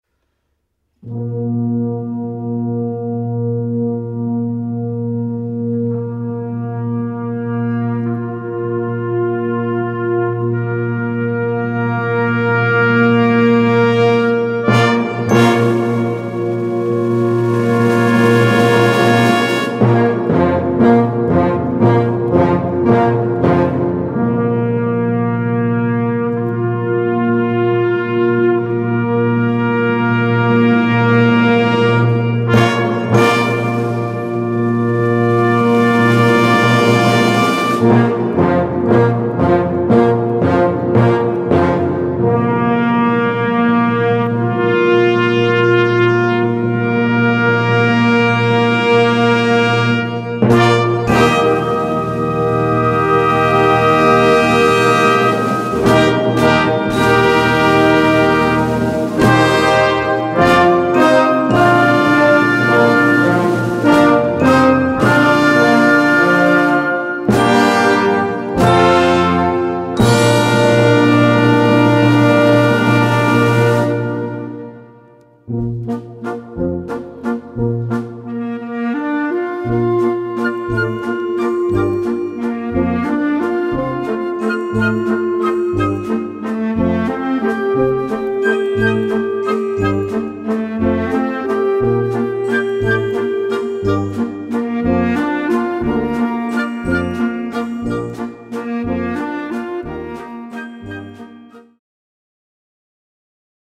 Gattung: 2 Strauss-Werke für Jugendblasorchester
Besetzung: Blasorchester